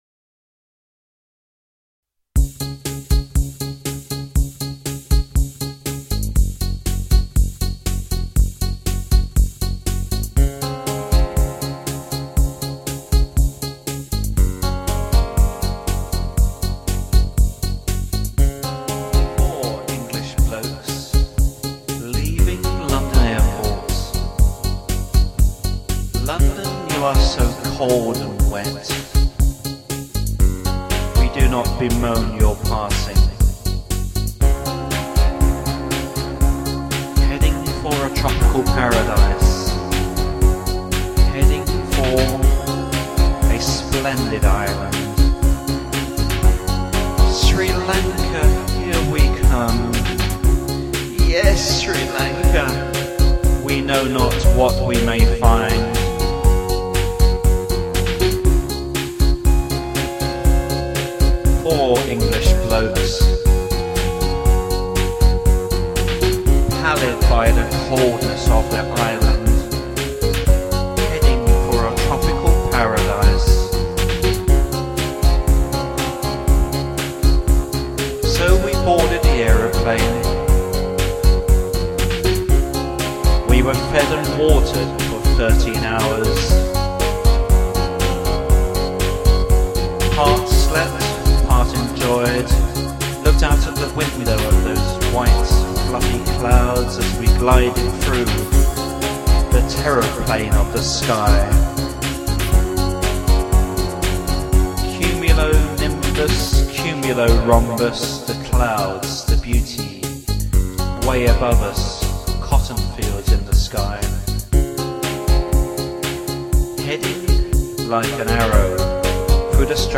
Electro musical poetry duo